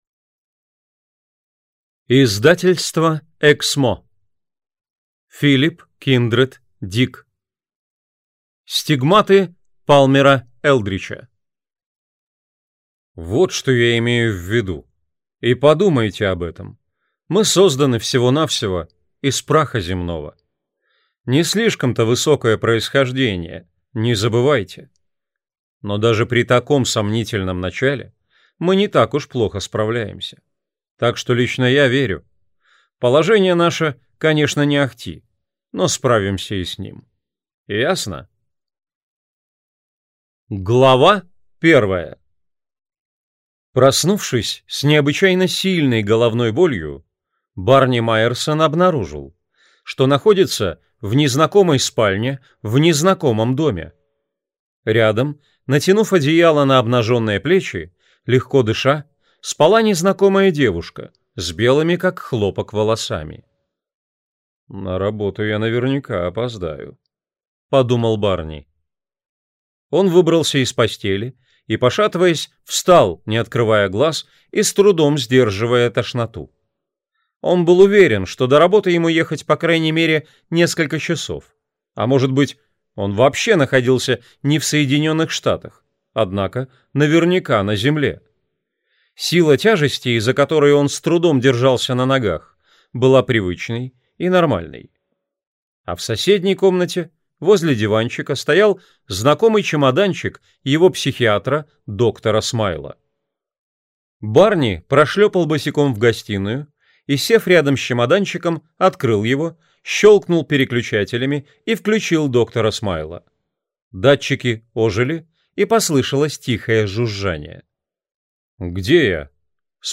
Аудиокнига Стигматы Палмера Элдрича | Библиотека аудиокниг